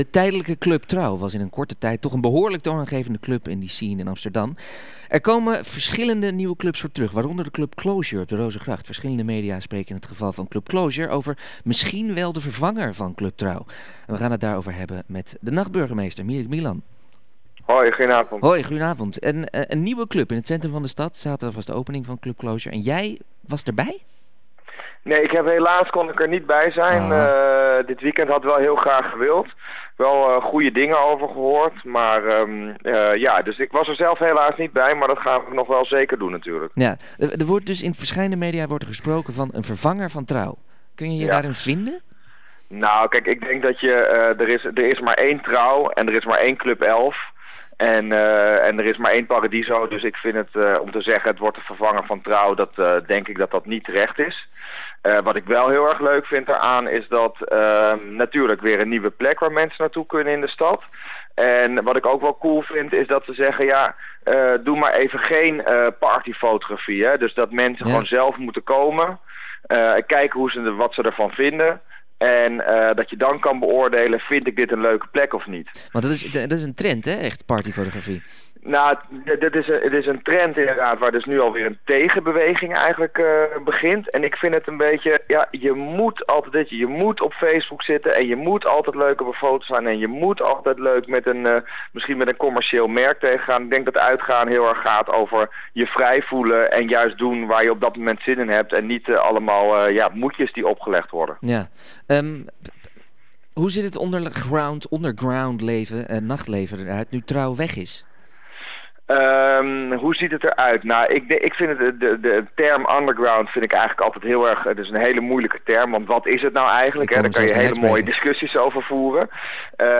In Ritme van de Stad spraken we over het nachtleven van Amsterdam met de nachtburgemeester: Mirik Milan.